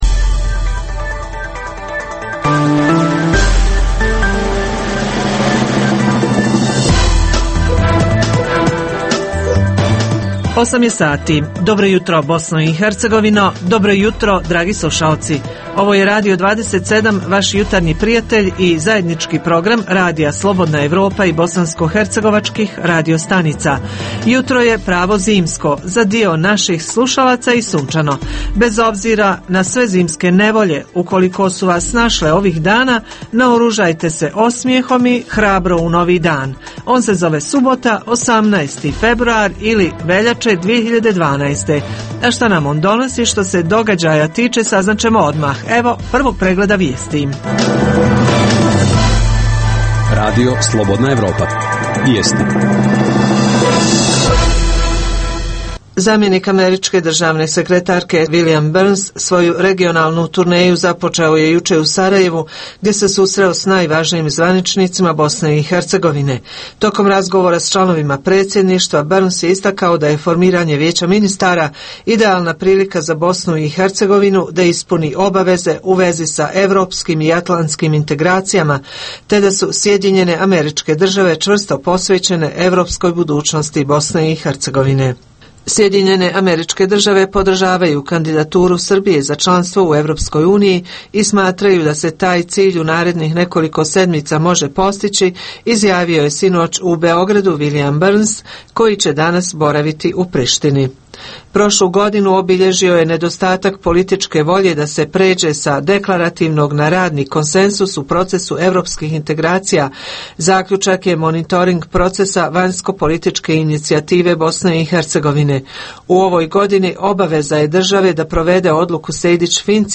U Jutarnjem programu između ostalog možete čuti - Javljanje reportera iz više mjesta u BiH o situaciji izazvanoj velikim snježnim padavima i mogućim posljedicama topljenja snijega.
- Uz tri pregleda vijesti, slušaoci mogu uživati i u ugodnoj muzici.